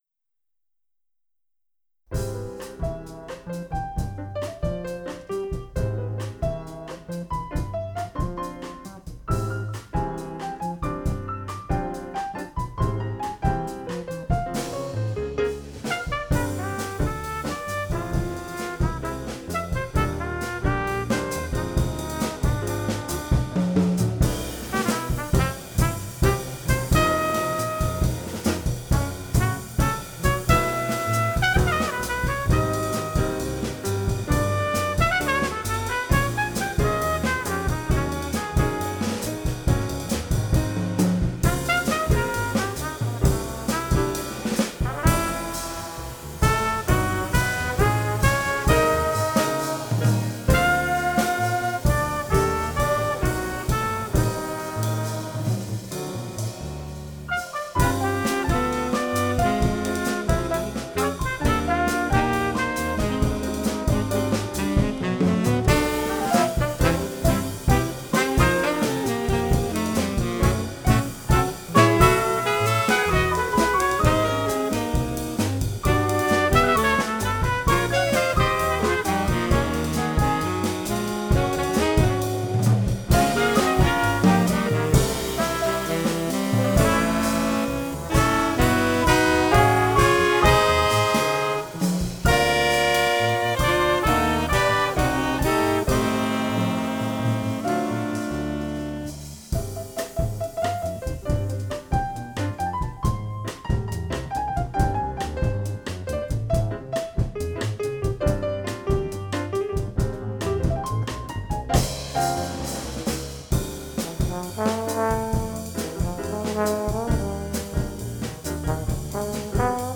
Sur un rythme de bossa-nova